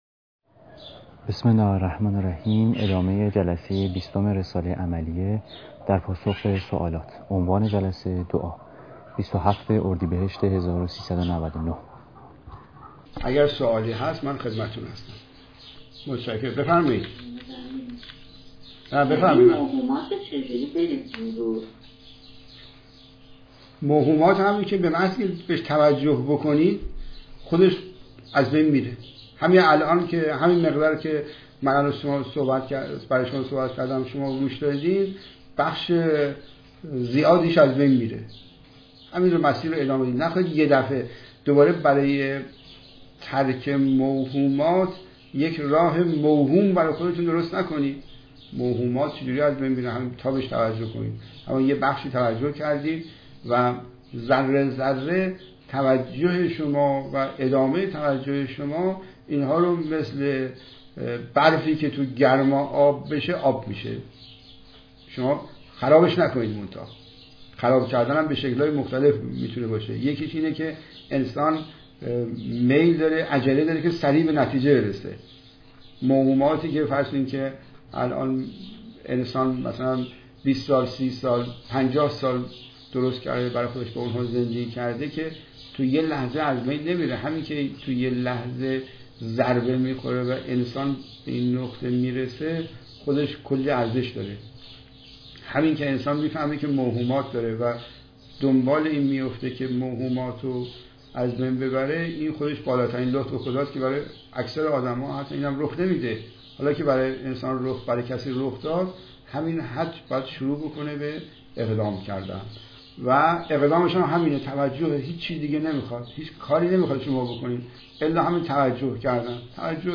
دعا (پرسش‌وپاسخ)